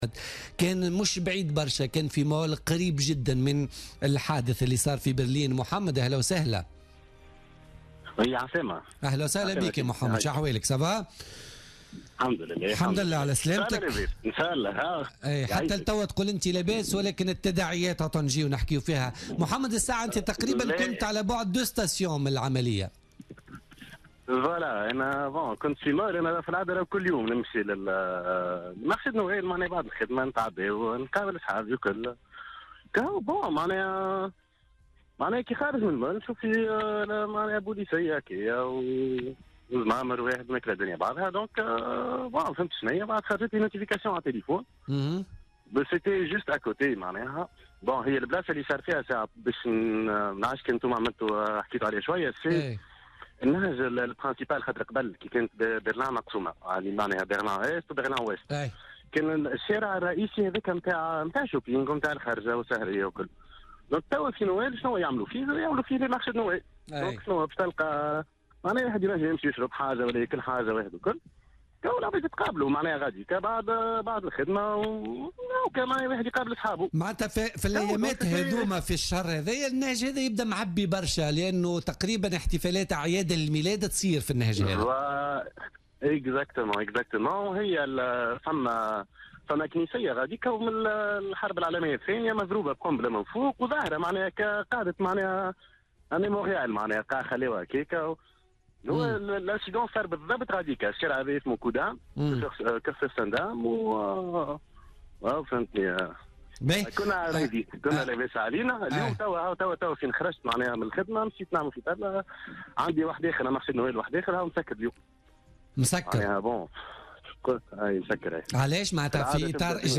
Attaque au camion à Berlin : Témoignage d'un ressortissant Tunisien